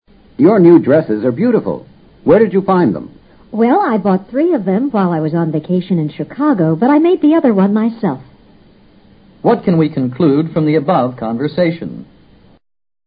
托福听力小对话【34】